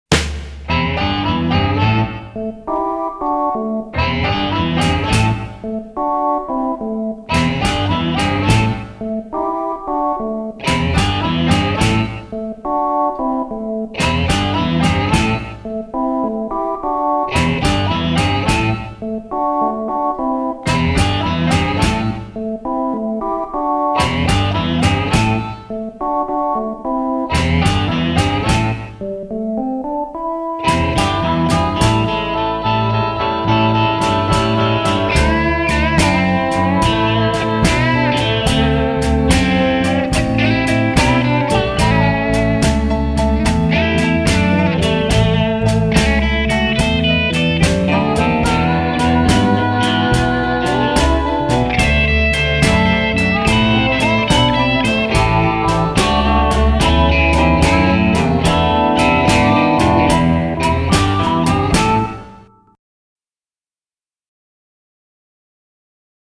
pour piano, orgue et synthé